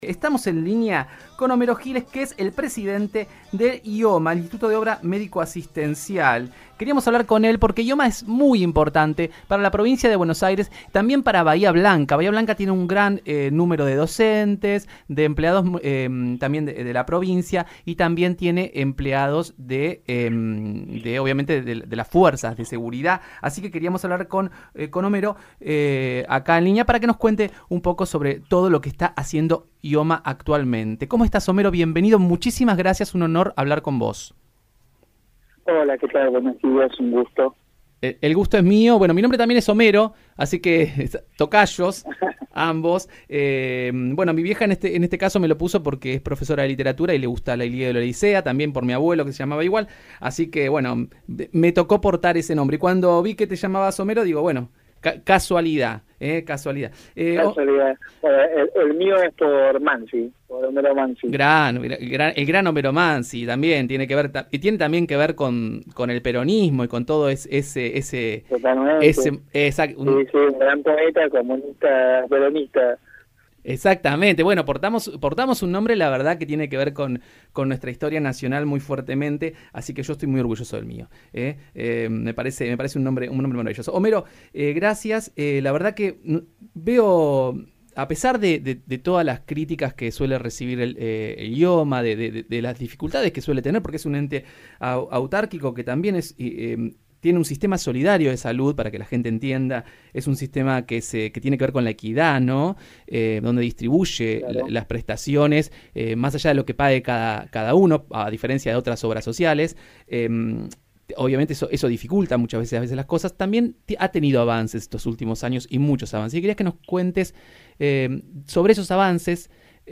Homero Giles, presidente de IOMA, conversó con el programa “Somos como somos” por Radio 10 Bahía Blanca sobre los avances que tuvo la obra social desde el inicio de su gestión.